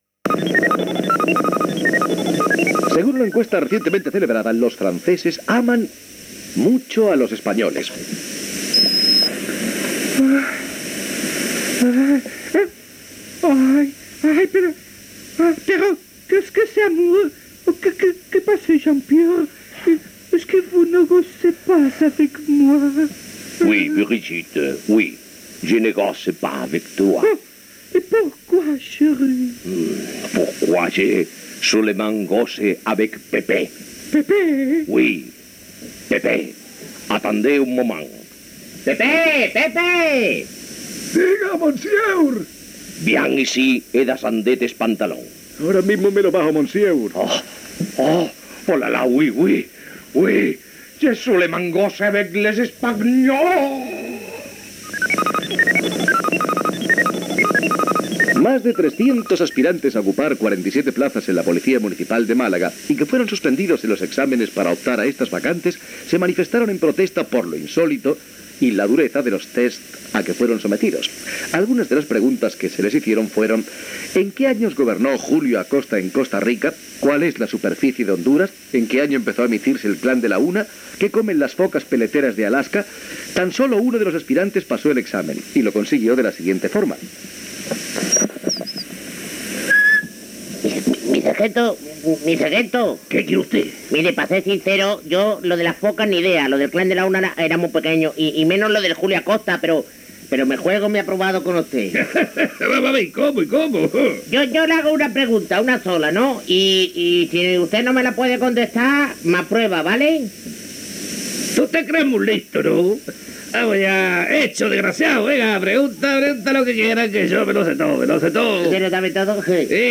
Noticiari: els francesos estimen els espanyols, els aspirants a ser policies locals de Màlaga.
Entreteniment